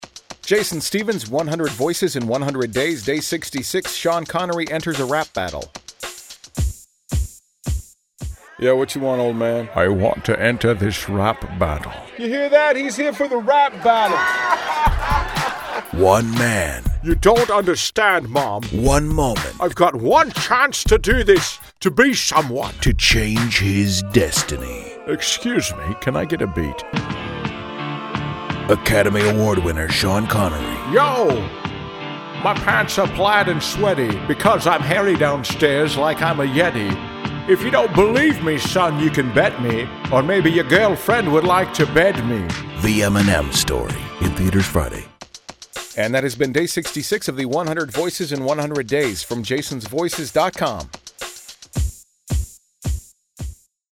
Tags: celebrity voice over, Sean Connery Impression, voice match